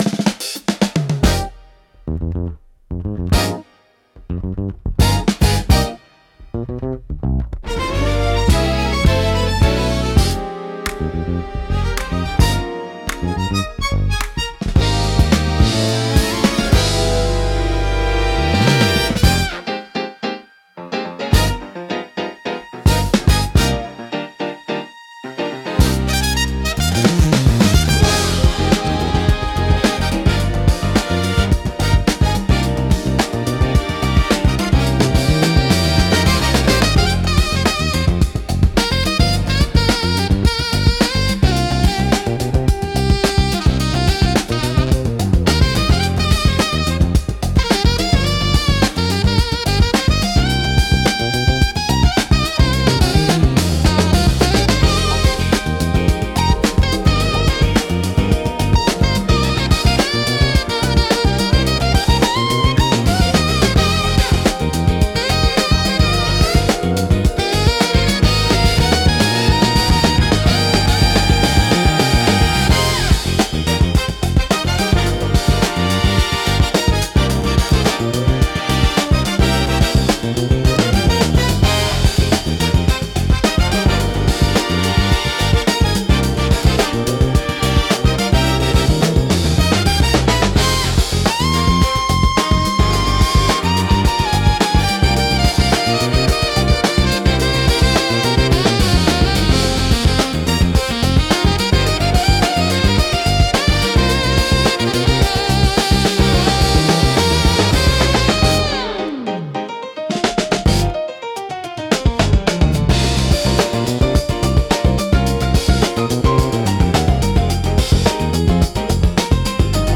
落ち着きつつも躍動感があり、聴く人の気分を盛り上げつつリラックスさせる効果があります。